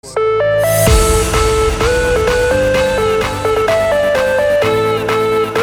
• Качество: 320, Stereo
dance
без слов
club
electro house